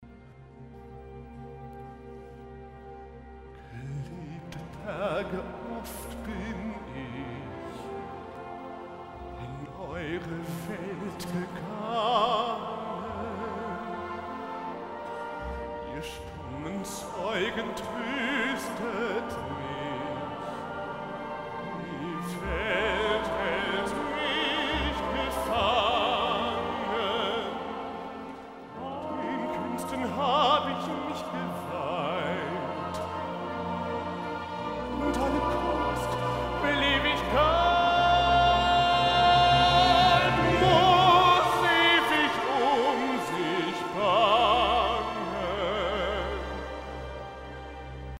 Live- Aufnahme